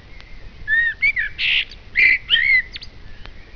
amsel.wav